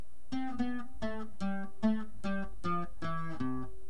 bajo :
caminado_intro.mp3